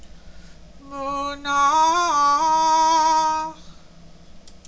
munch.wav